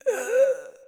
SFX_Mavka_Defeated_02.wav